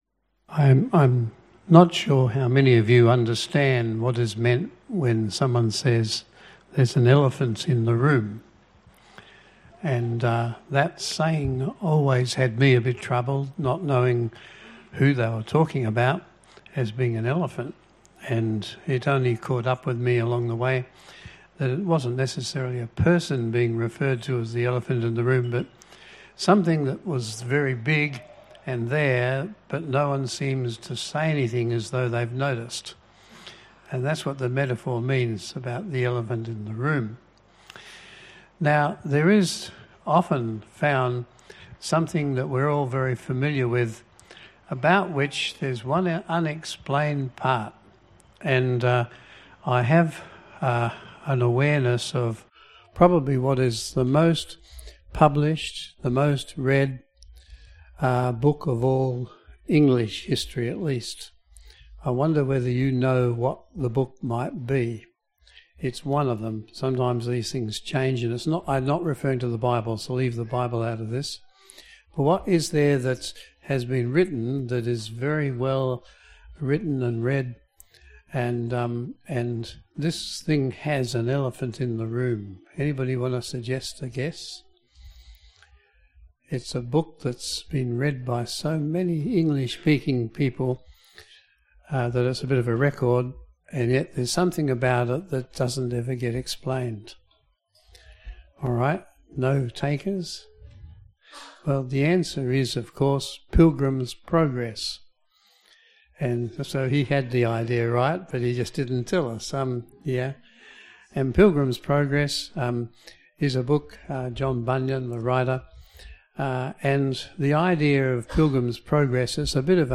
Service Type: AM Service